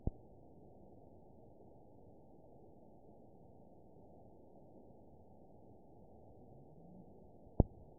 event 922872 date 04/29/25 time 09:59:44 GMT (1 month, 2 weeks ago) score 7.59 location TSS-AB01 detected by nrw target species NRW annotations +NRW Spectrogram: Frequency (kHz) vs. Time (s) audio not available .wav